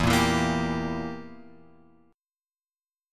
Edim chord {0 1 2 3 x 3} chord
E-Diminished-E-0,1,2,3,x,3.m4a